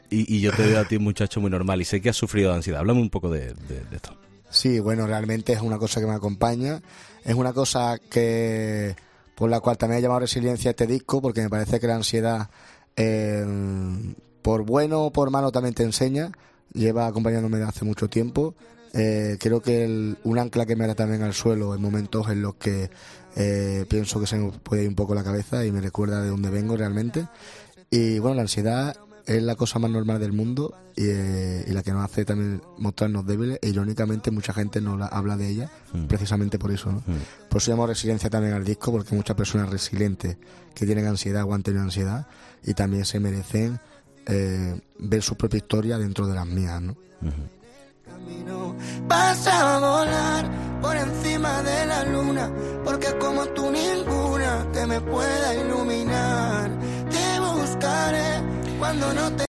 El cantante se encuentra presentando su nuevo disco, 'Resiliencia', que se ha estrenado este mismo 11 de noviembre.